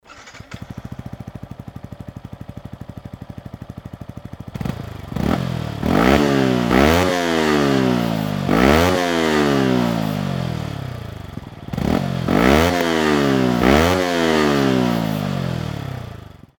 空ふかしの排気音を収録してきたのでどうぞ・・
XSR155 純正マフラー
2本とも同じ距離から録音していますが
純正マフラーとの音質の違いはあるものの
音量自体はほとんど同じなのが分かると思います。